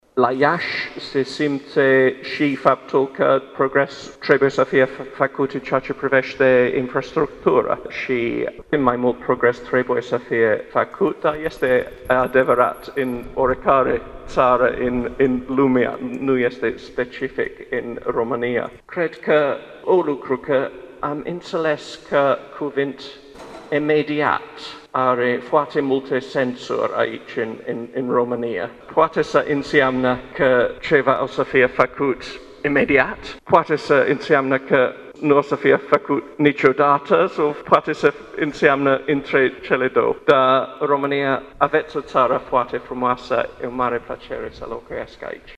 Aprecierea aparţine ambasadorului britanic la Bucureşti, Paul Brumell, care astăzi s-a aflat la Iaşi.
Referindu-se la infrastructura Iaşului, ambasadorul Paul Brumell, a arătat că în acest domeniu mai sunt necesare investiţii: